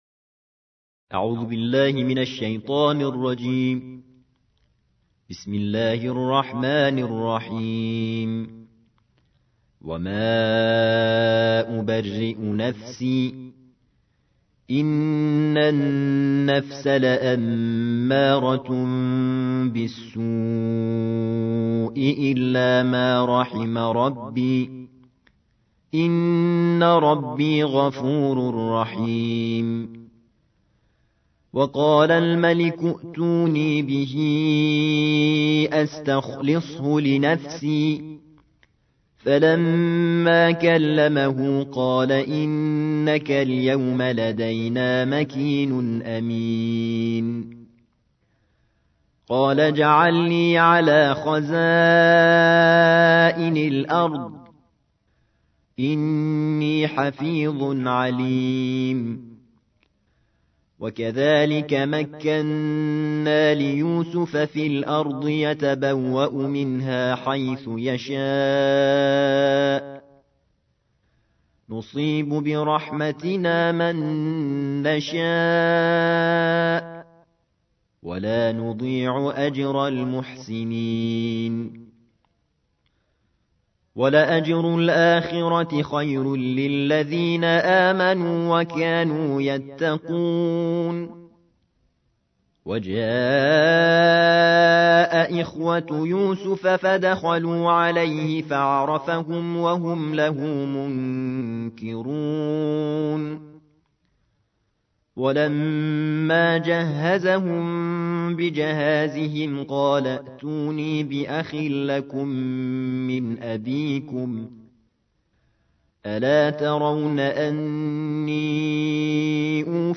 الجزء الثالث عشر / القارئ